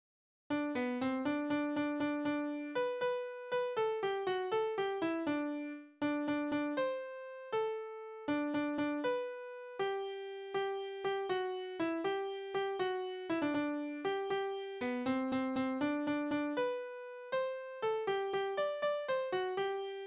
Tonart: G-Dur
Taktart: 3/8
Tonumfang: große Dezime
Besetzung: vokal